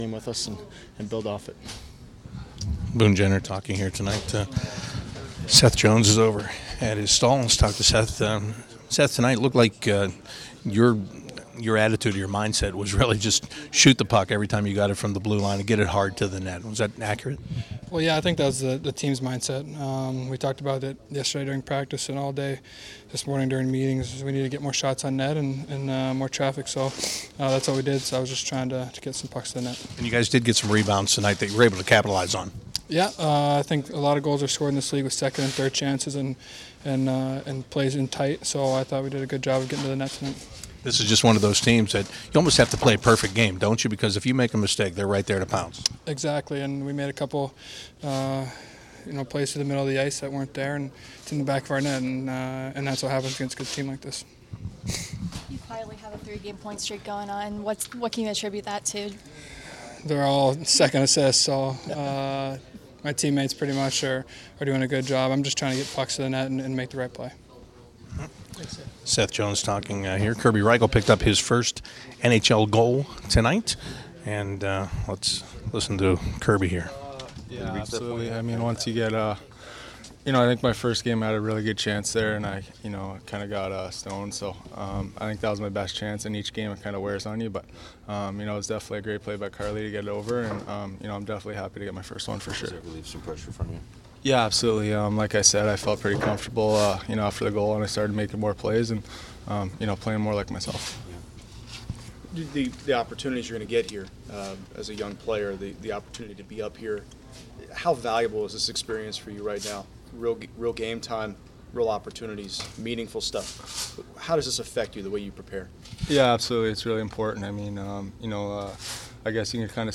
Post Game Player Interviews